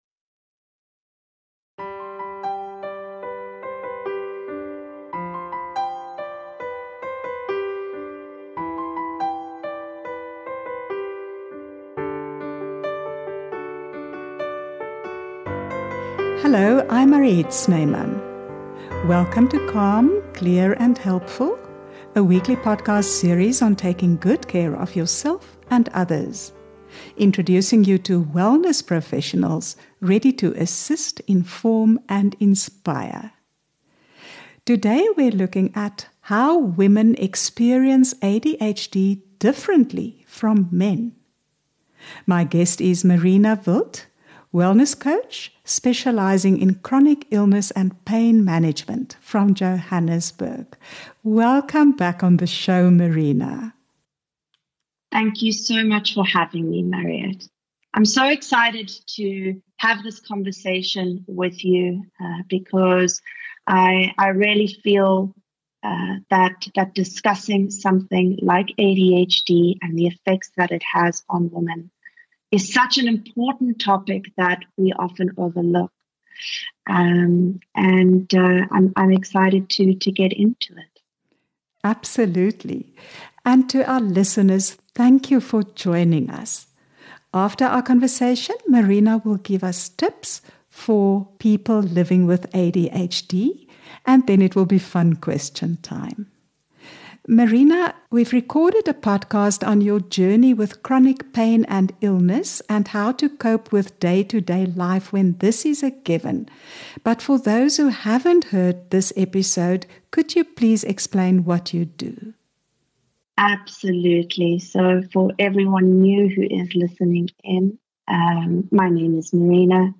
interviews a range of experts on holistic health, love relationships, parenting, and life's phases and challenges. Join us each Tuesday for fresh insights, practical know-how, and for conversations from the heart.